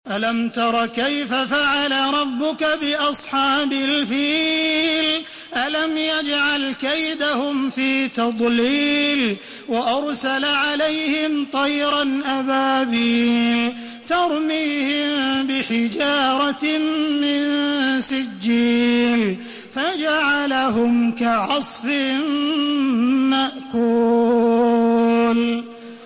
Surah الفيل MP3 by تراويح الحرم المكي 1432 in حفص عن عاصم narration.
مرتل